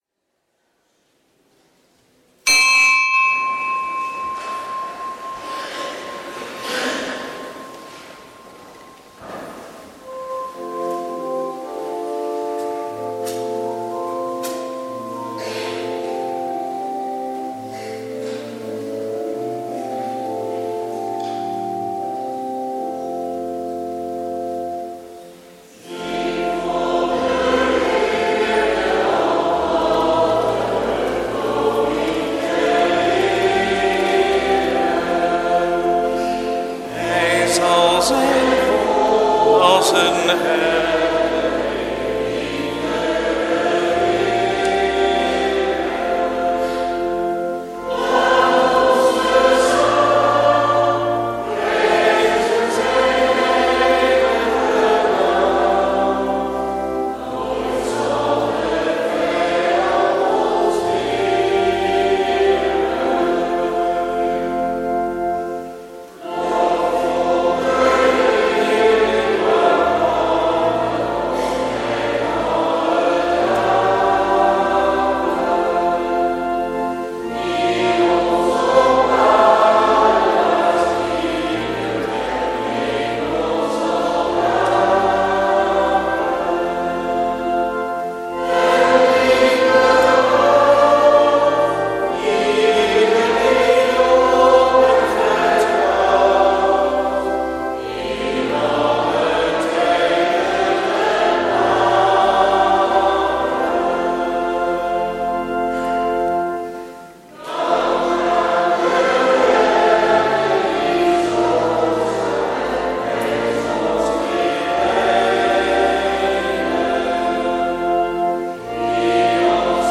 Eucharistieviering beluisteren (MP3)